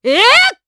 Glenwys-Vox_Attack3_jp.wav